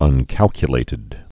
(ŭn-kălkyə-lātĭd)